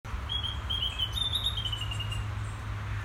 Vroege vogels forum - Onbekende zang
Ik hoor al een paar dagen een vogel die repeterend (met tussenpozen van ca 20 seconden) dit geluid laat horen. Hij begeeft zich in de boomkruinen en struikgewas.
Ik verbeeld het me wellicht maar het lijkt erop dat hij heel af en toe het geluid van een zwartkop laat horen maar dan vervolgens overheerst toch dit riedeltje.
vreemdevogel.mp3